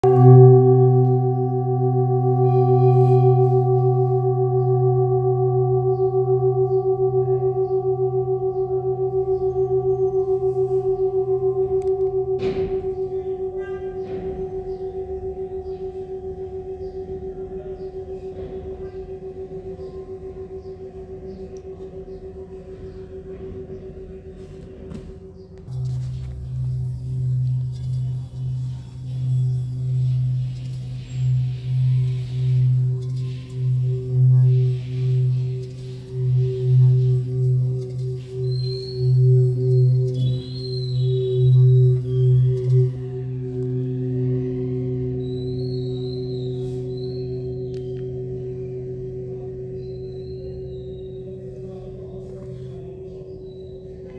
Handmade Singing Bowls-31560
Singing Bowl, Buddhist Hand Beaten, Antique Finishing, Select Accessories
Material Seven Bronze Metal